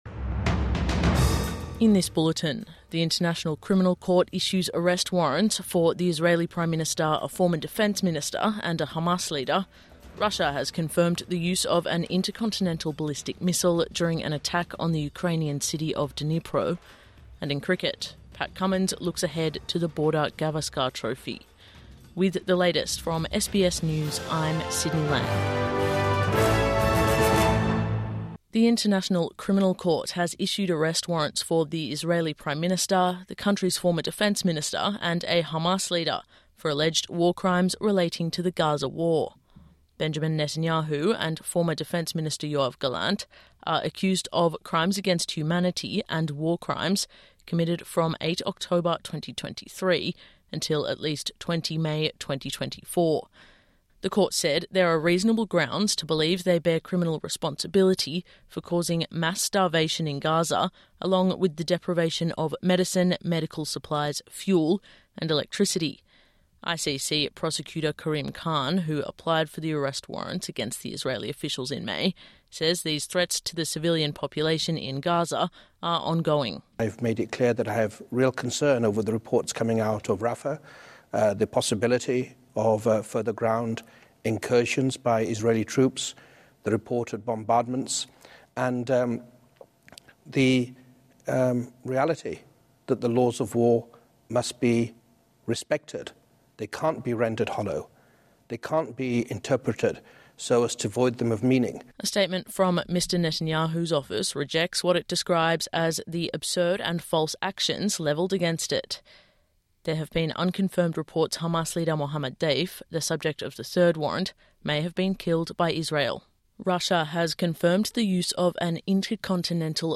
Morning News Bulletin 22 November 2024